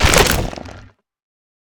creaking_attack4.ogg